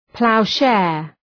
Προφορά
{‘plaʋ,ʃeər} (Ουσιαστικό) ● υνίο